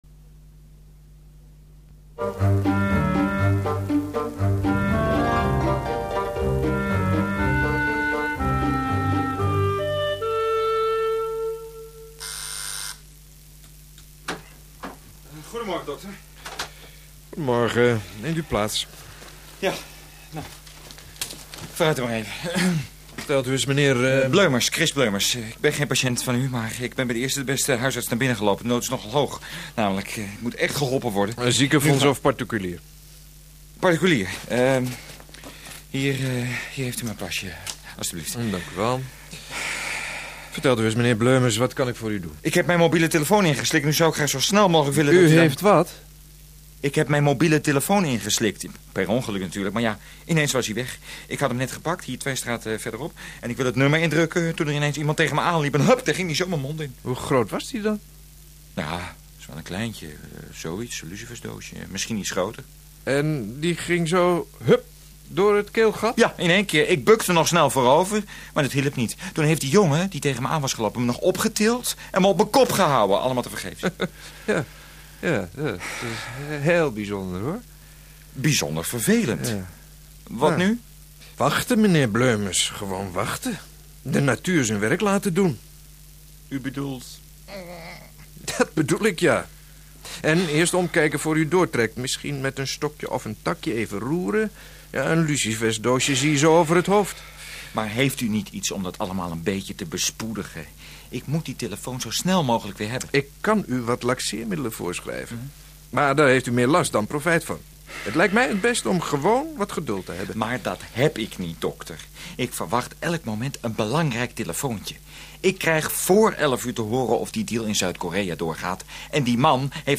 In dit korte humoristische hoorspel: Normaal gesproken verwacht men dat een buikspreker geen mobieltje nodig heeft om geluid uit zijn buik te krijgen.